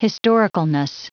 Prononciation du mot historicalness en anglais (fichier audio)
Prononciation du mot : historicalness